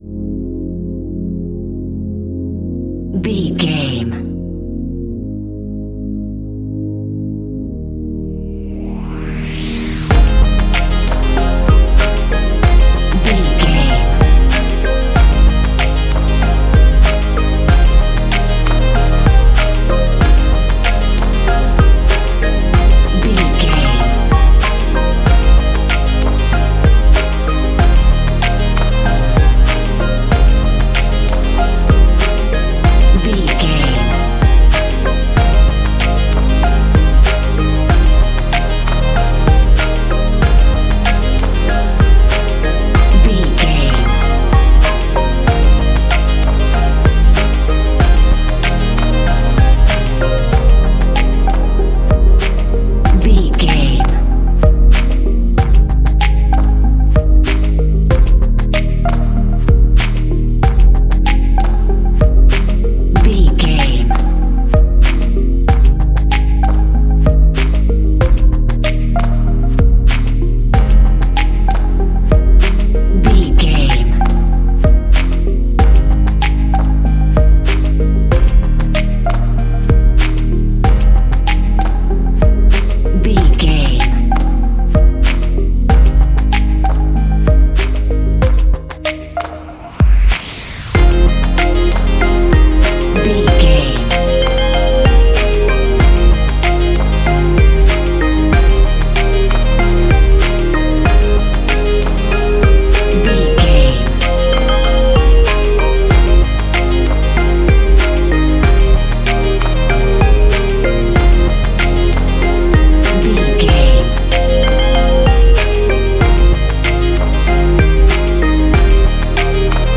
Ionian/Major
F♯
house
electro dance
synths
trance